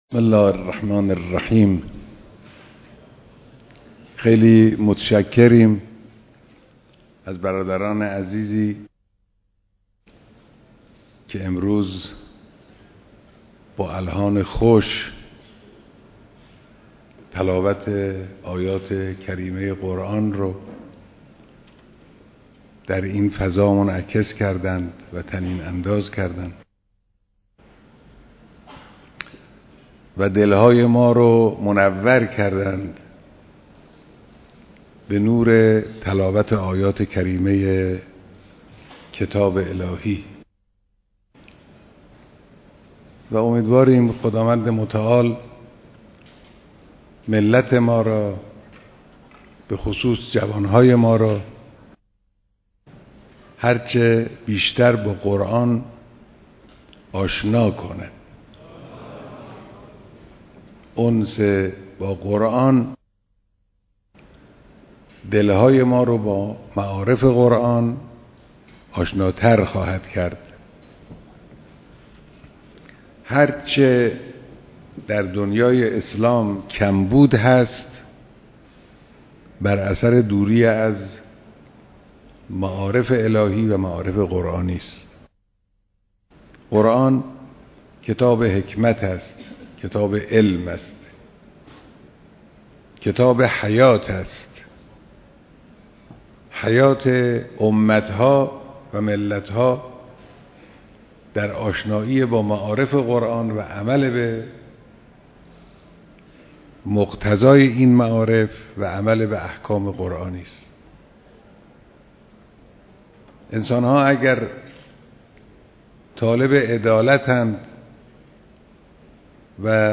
دیدار جمعی از قاریان، حافظان و اساتید قرآنی
بیانات در محفل انس با قرآن‌ كریم